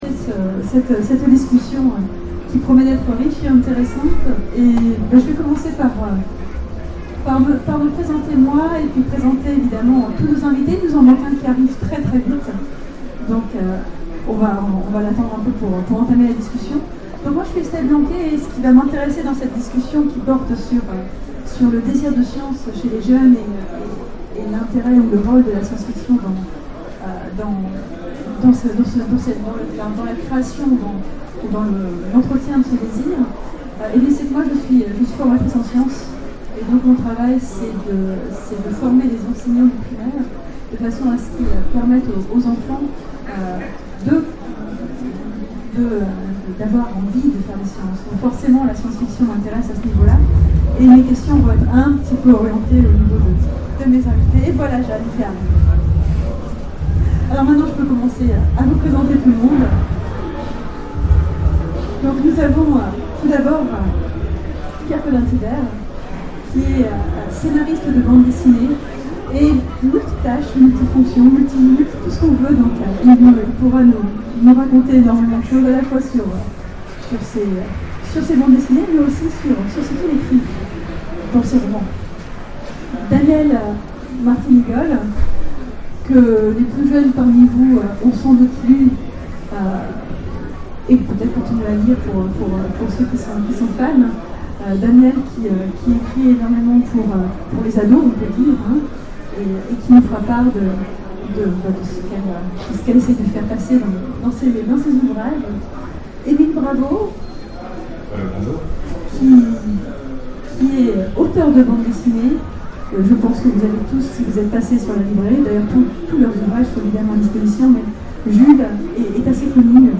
Utopiales 12 : Conférence La science-fiction et le désir de science chez les jeunes